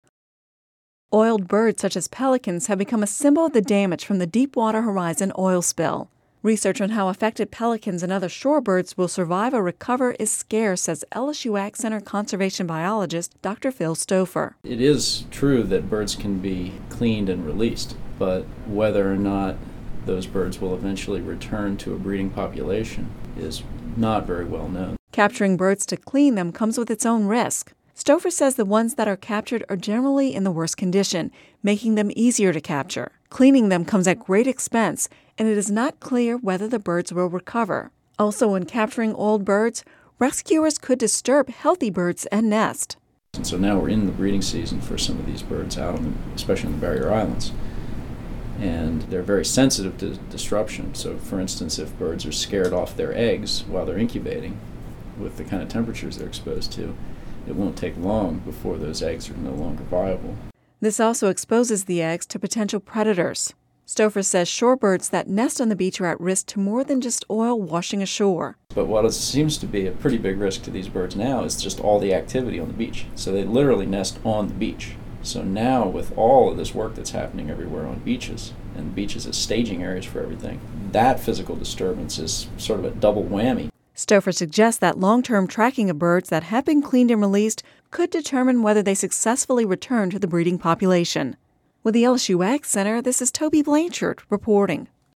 (Radio News 07/05/10) Oiled birds such as pelicans have become a symbol of the damage from the Deepwater Horizon oil spill.